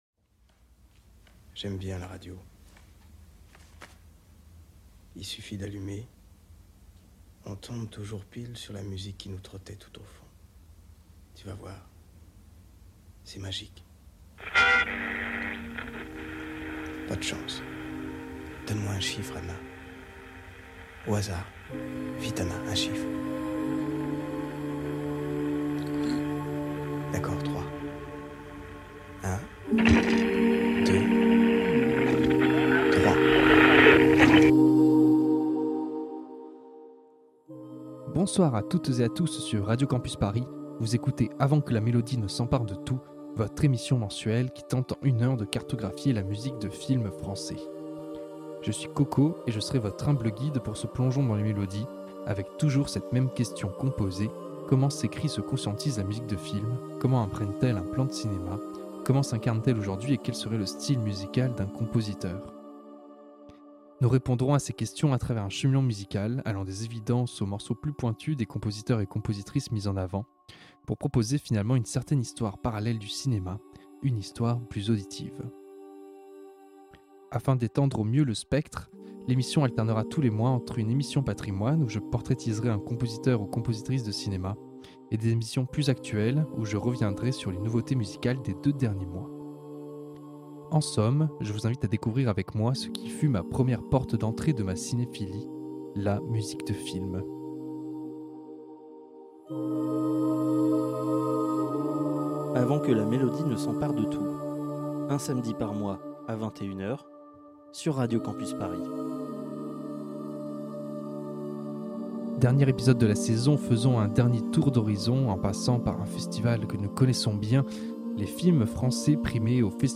Type Musicale Classique & jazz